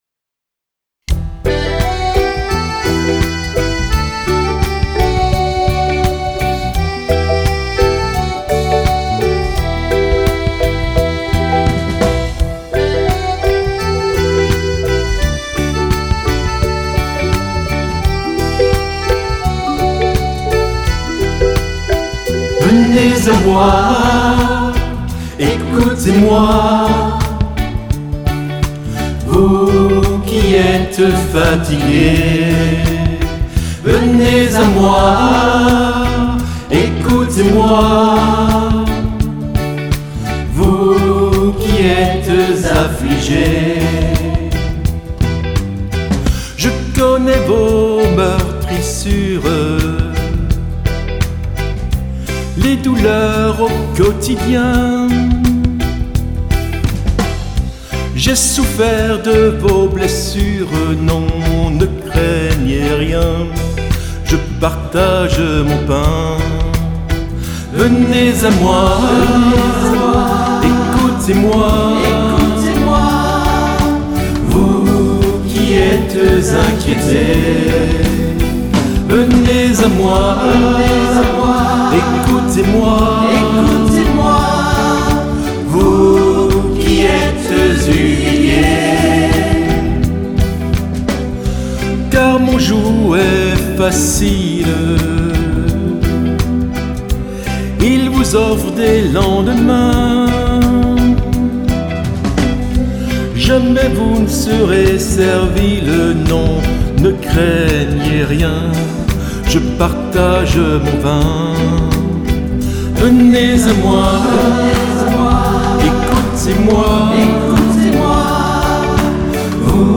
Version avec voix pour les célébrations*